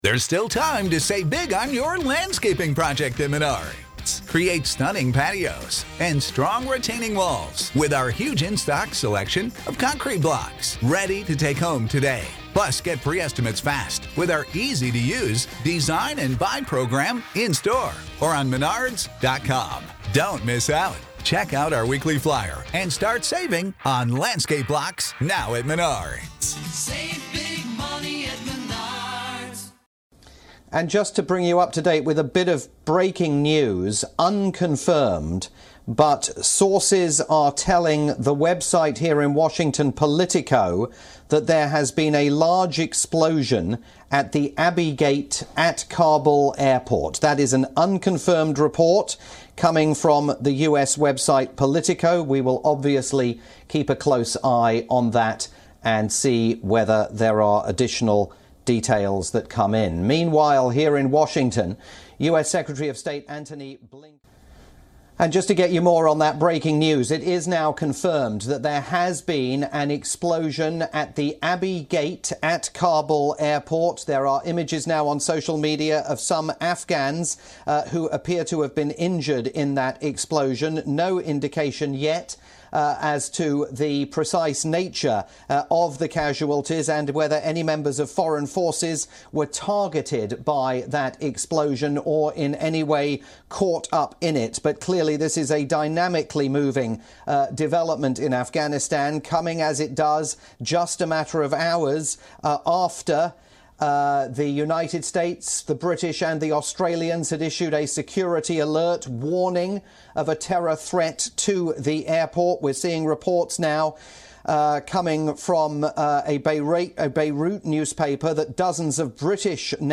7pm India-time news bulletin for all-news WION, India's global news channel.